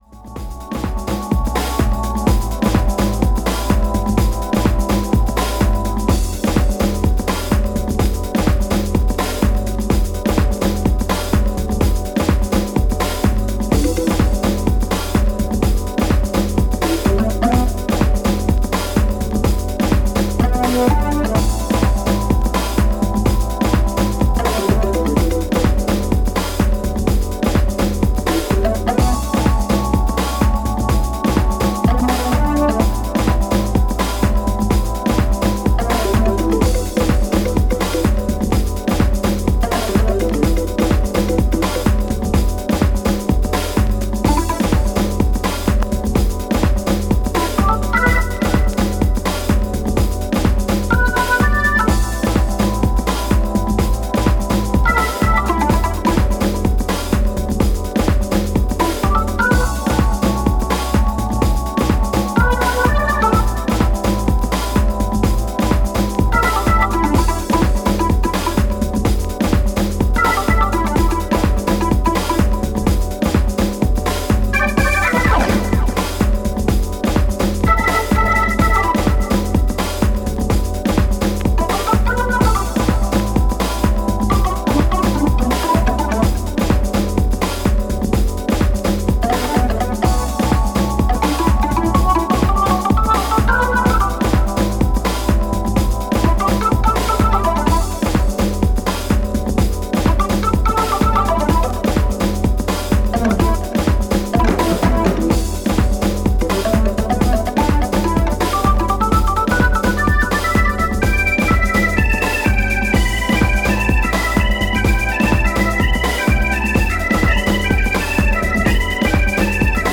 STYLE House / Deep House